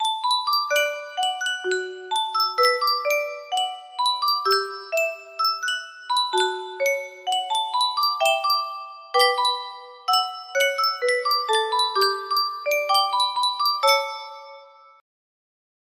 Yunsheng Music Box - Unknown Tune 1724 music box melody
Full range 60